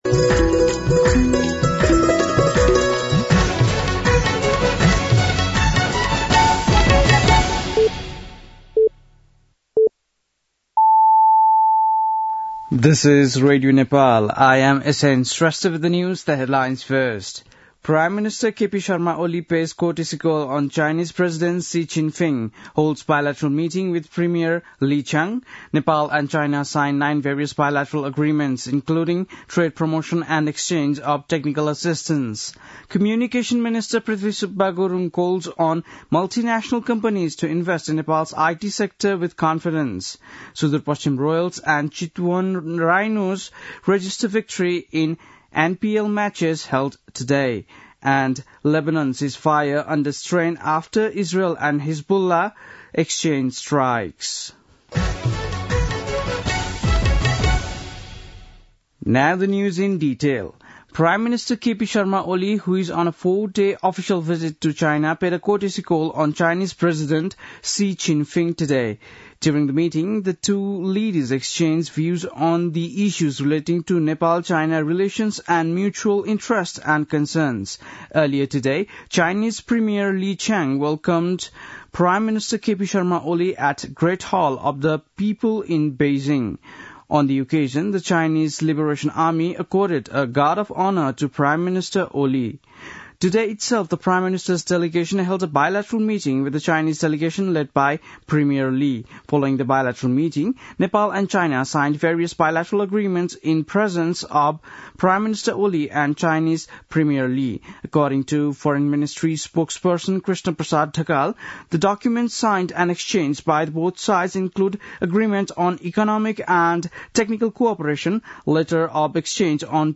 बेलुकी ८ बजेको अङ्ग्रेजी समाचार : १९ मंसिर , २०८१